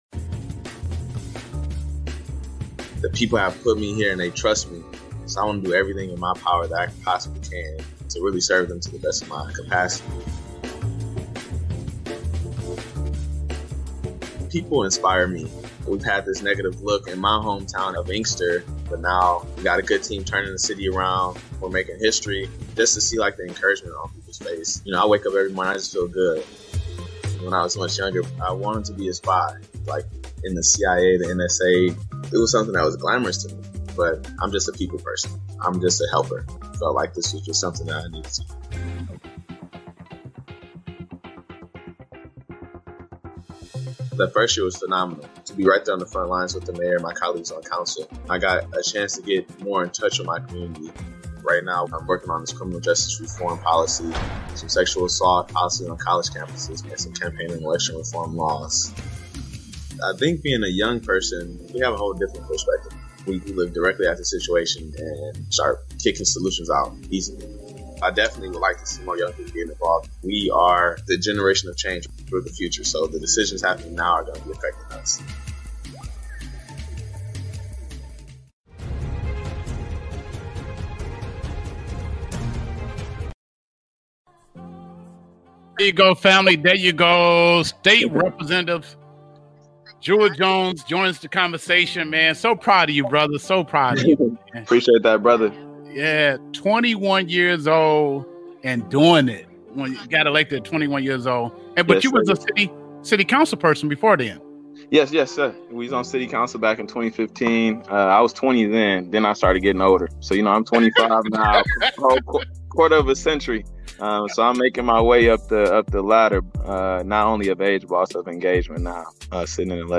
Interview with State Rep. Jewell Jones
Interview with Michigan Rep. Jewell Jones, the youngest person to ever be elected to a state office